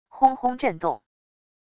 轰轰震动wav音频